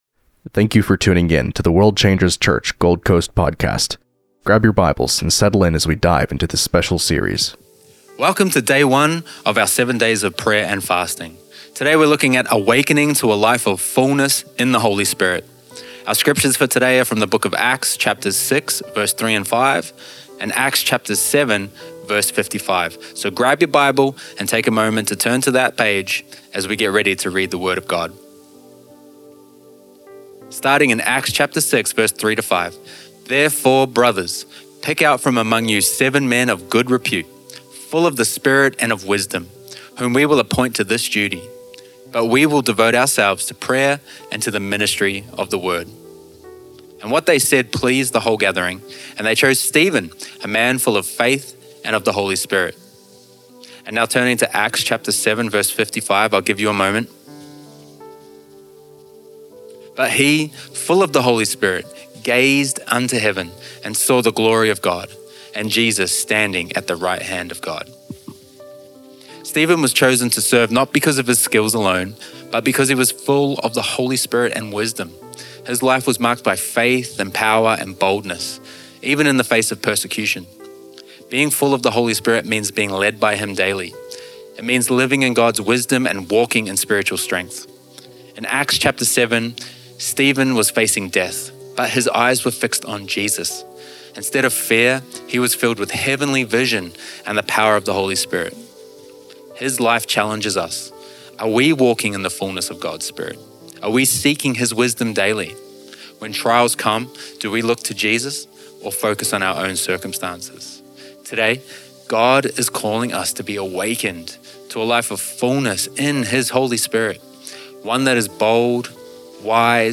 This devotional explores what it means to live a life fully led by the Holy Spirit. Drawing from Stephen’s example, it highlights the importance of wisdom, boldness, and faith.